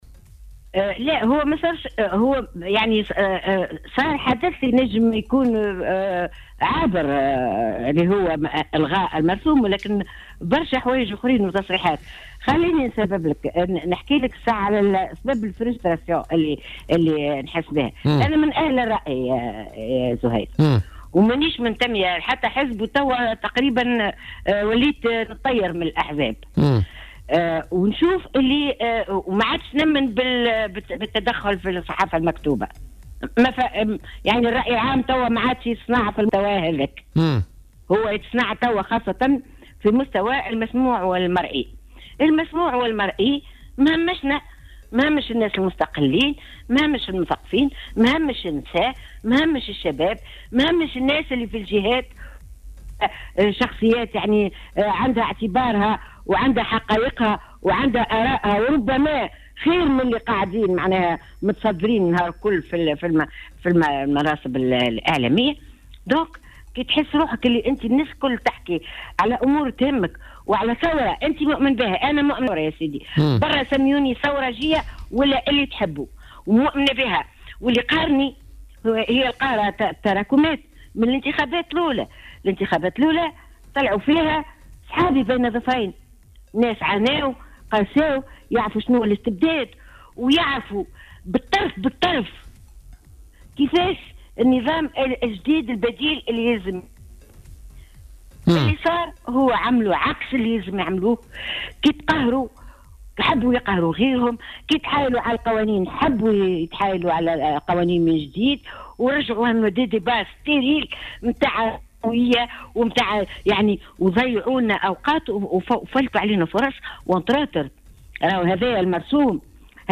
واعتبرت أم زياد، في تدخل لها عبر الهاتف خلال حصة بوليتيكا، أنه لا فرق بين من ادعى الثورجية من الترويكا وبين من يحكم البلاد حاليا والذي يمثل المنظومة السابقة التي قامت ضدها الثورة، فكلاهما تبنى نظرية المؤامرة كلما لاحظوا حراكا شعبيا منتقدا لأدائهم، إذ تبنى النظرية الباجي قائد السبسي في مواجهة حملة وينو البترول وكذلك الشأن بالنسبة للترويكا حيث ذكرت أم زياد بخطاب طارق الكحلاوي-الذي كان ضيف نفس الحصة من بوليتيكا- في شارع الحبيب بورقيبة.